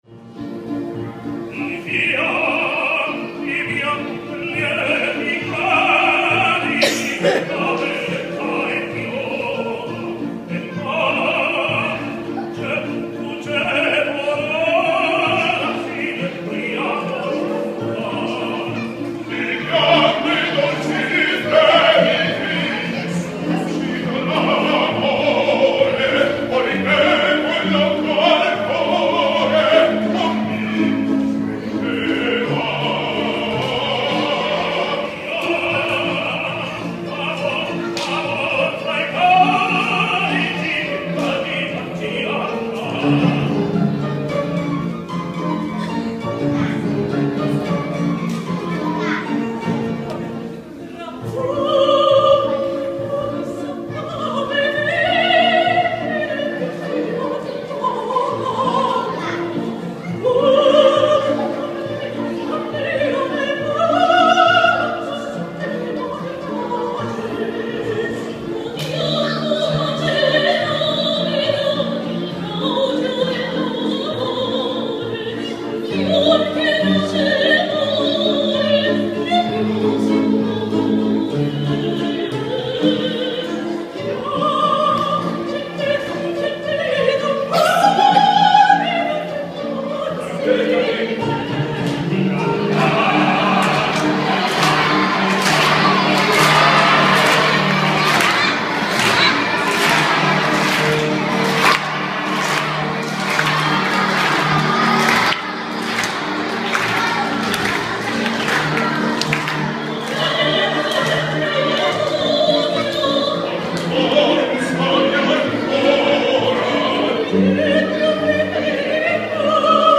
tenor chileno